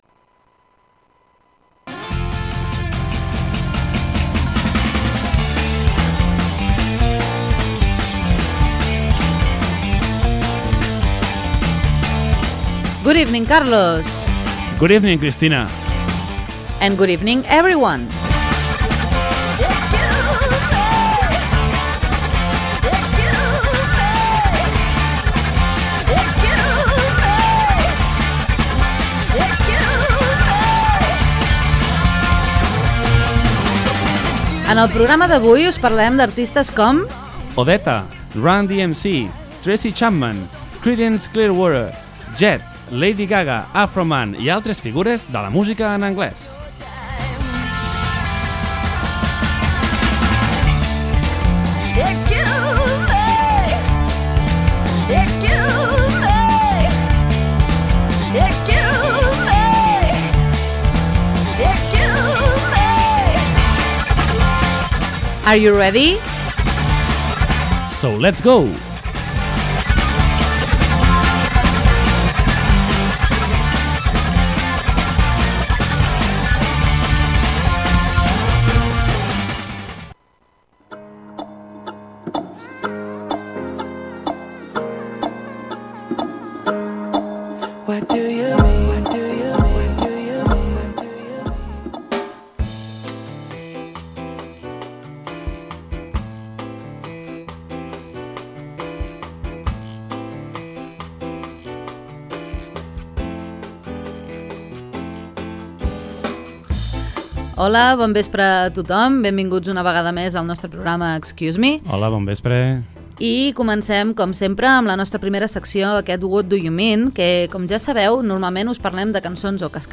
En aquesta edició ens porten cançons d’artistes femenines de veu greu, cançons en les quals apareixen preguntes, les frases amagades en dues cançons com cada setmana i les respostes a les preguntes dels oients.